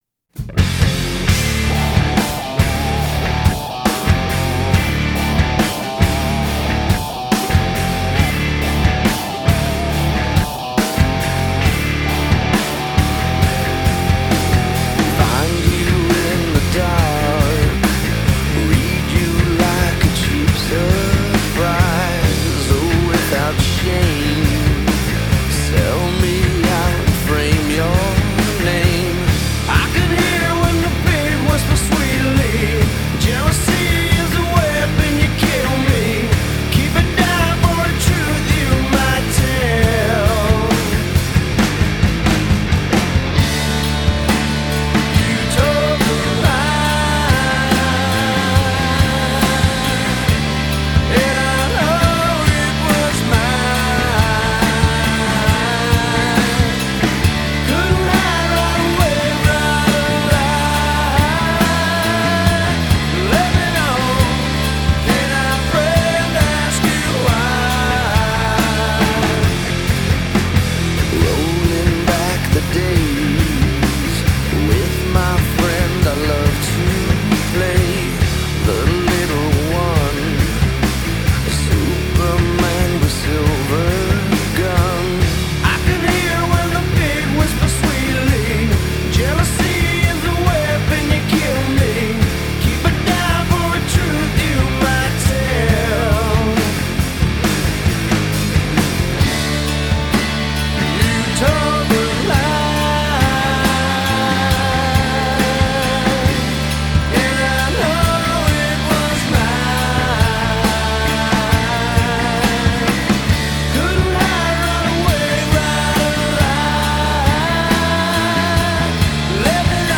融入爵士、藍調、放克等不同風格